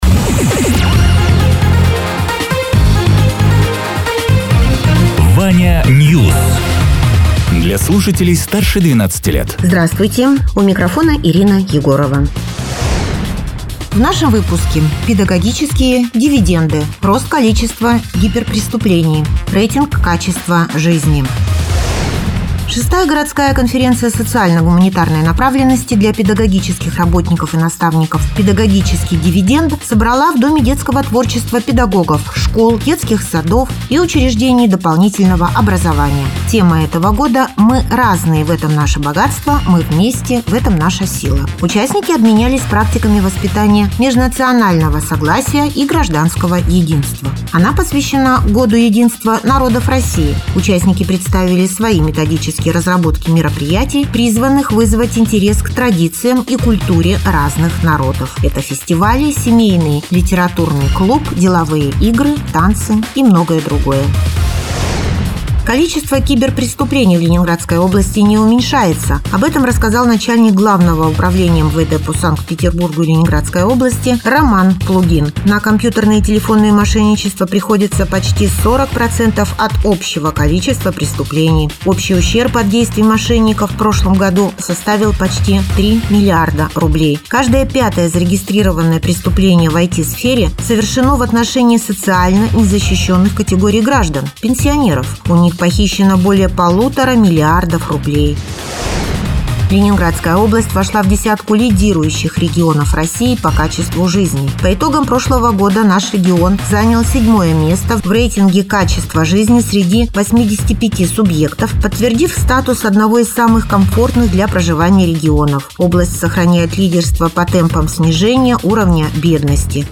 Радио ТЕРА 14.03.2026_08.00_Новости_Соснового_Бора